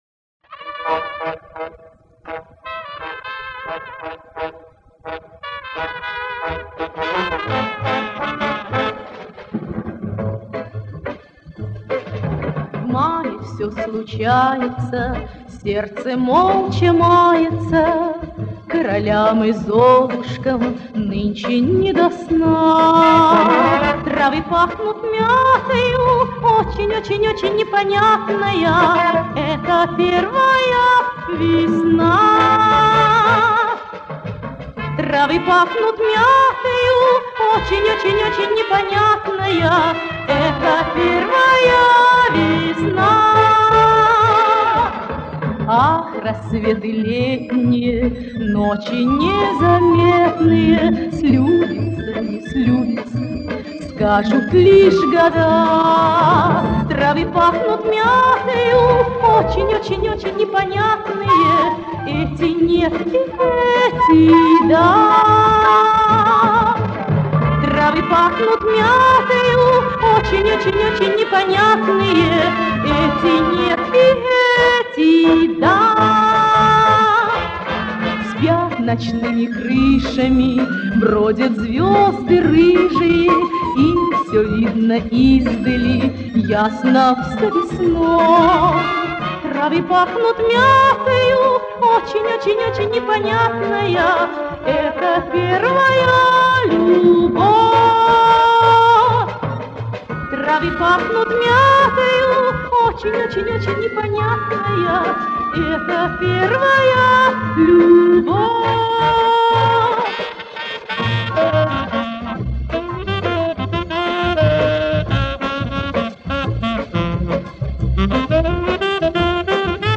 По-моему, с легким акцентом исполнение.
"трыавы" тоже с усилием....Может кто из прибалтов